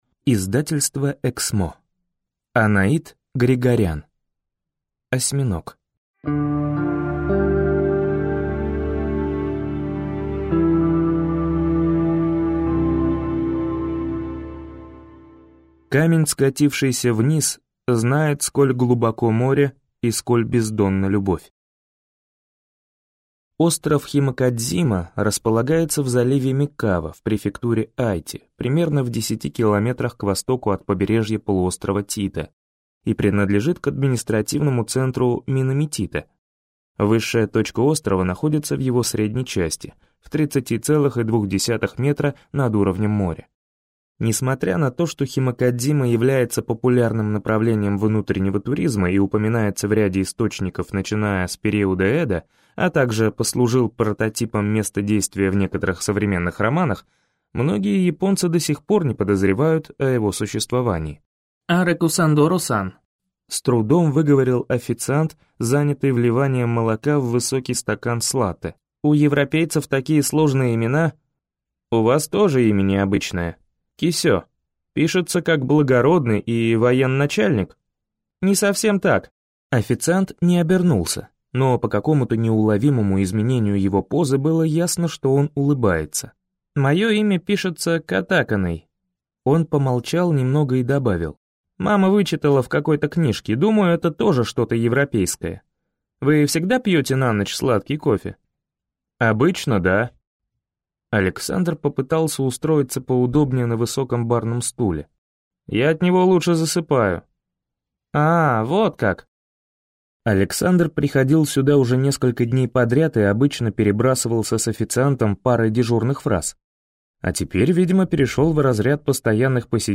Аудиокнига Осьминог | Библиотека аудиокниг